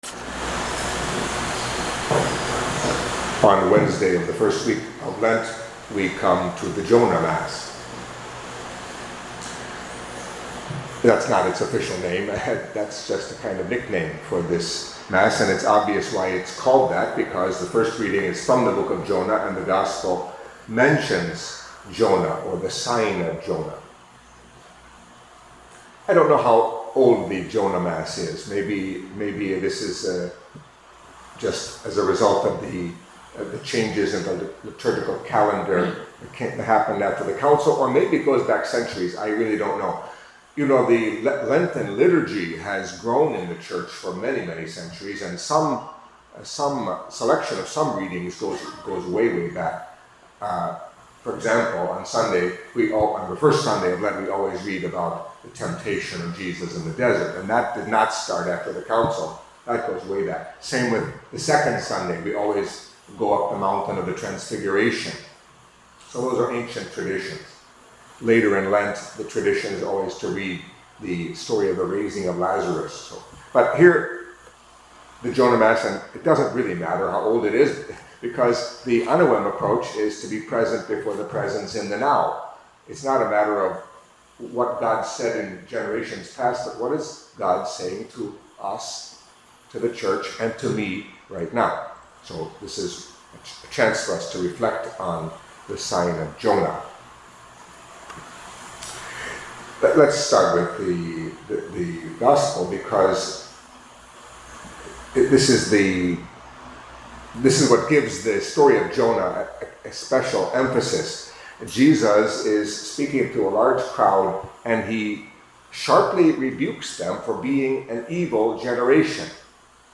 Catholic Mass homily for Wednesday of the First Week of Lent